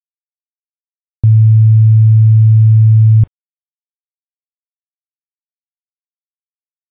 here to listen to a sine wave at 110 Hz.